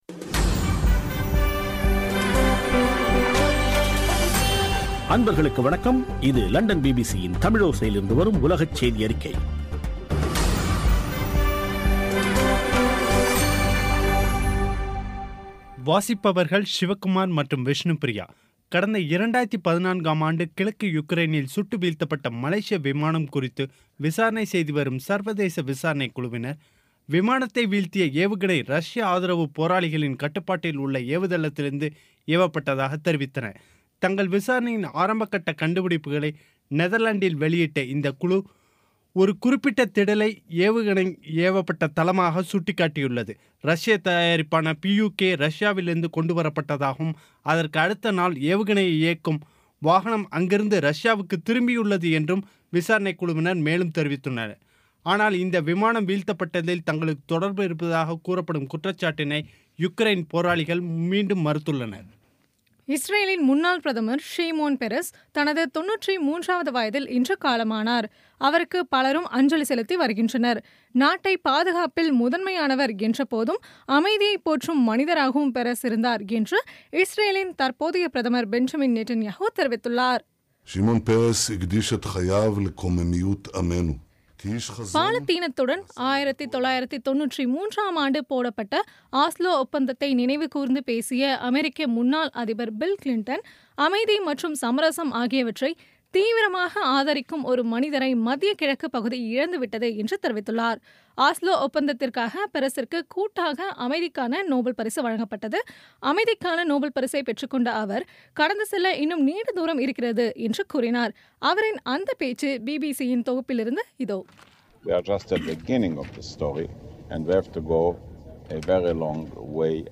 பி பி சி தமிழோசை செய்தியறிக்கை (28/09/2016)